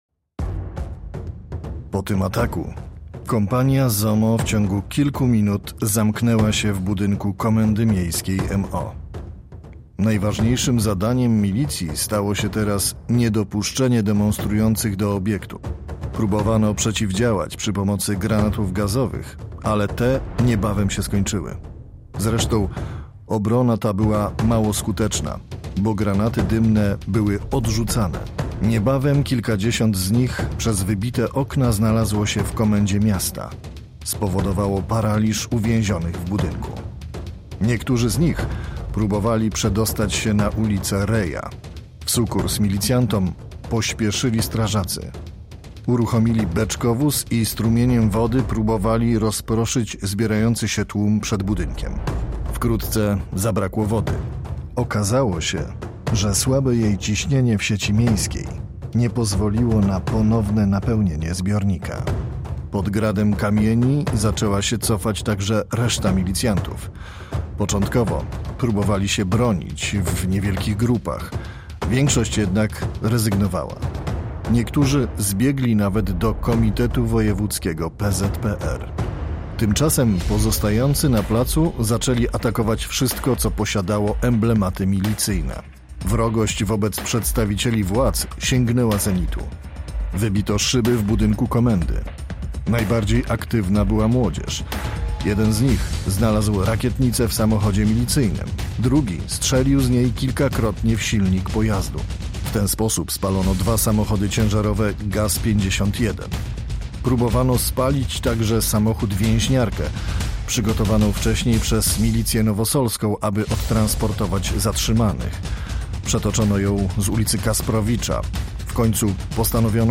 Z okazji 60. rocznicy Wydarzeń Zielonogórskich 30 maja 1960 r. Radio Zielona Góra przygotowało okolicznościowy radiobook opisujący godzina po godzinie, przebieg protestów na ulicach naszego miasta.
Montaż i udźwiękowienie
Lektor i koncepcja